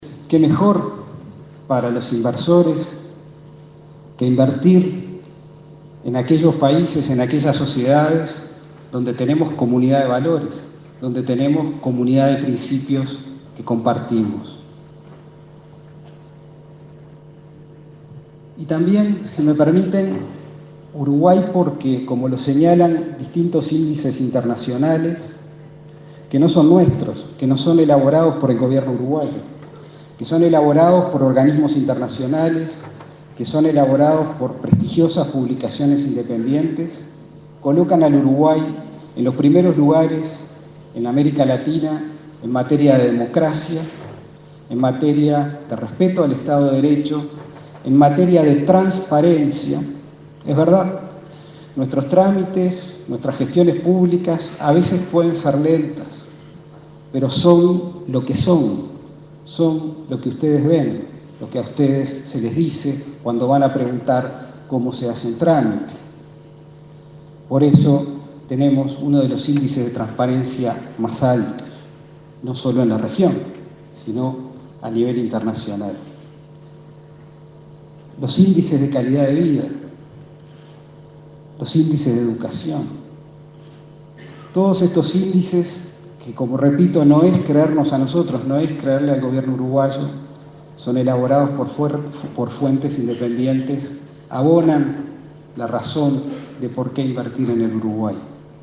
“Uruguay se encuentra, según estudios de organismos internacionales, en los primeros lugares en América Latina en democracia, respeto al estado de derecho, transparencia y educación, indicadores que abonan la razón del porqué invertir en este país", destacó el canciller interino, José Luis Cancela, en la apertura del I Foro de Inversión Europea en Uruguay.